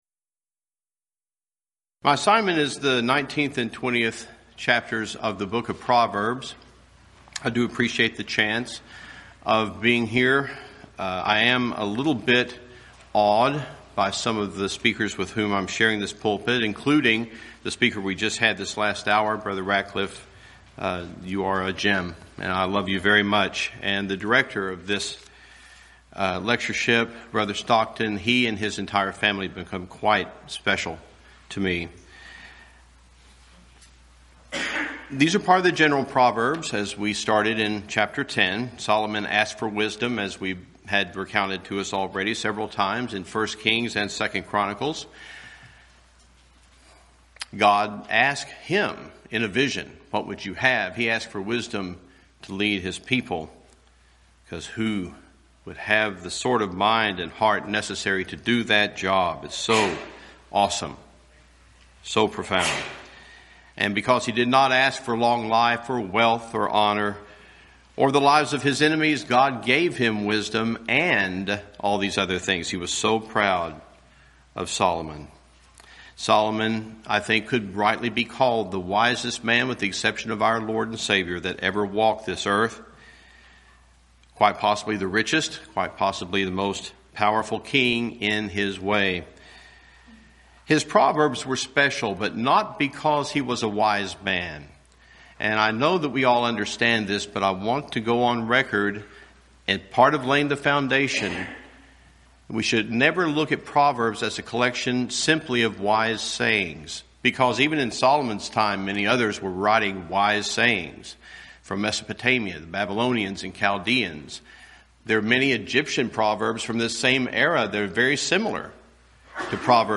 Event: 13th Annual Schertz Lectures
lecture